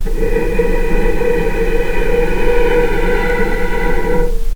vc-B4-pp.AIF